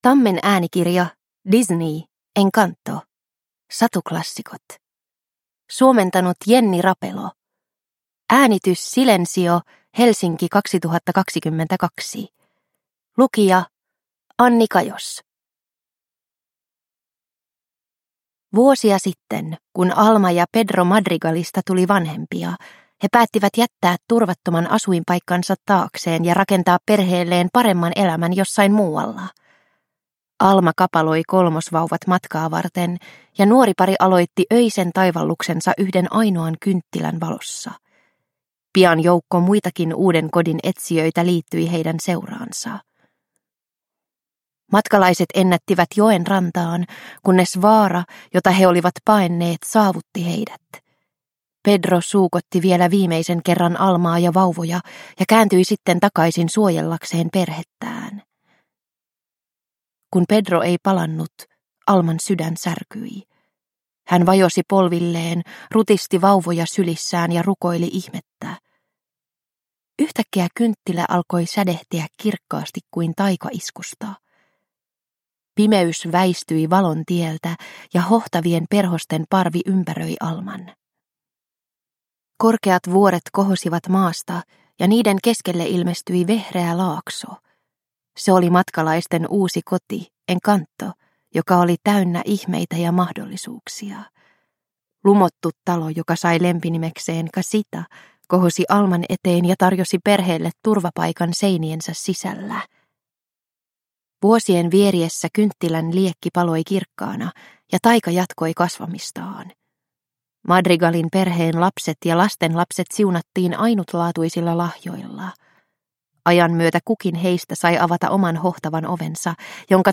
Disney. Encanto. Satuklassikot – Ljudbok – Laddas ner
Disneyn lumoavan Encanto-elokuvan tarina ihastuttaa kuulijaansa äänikirjana.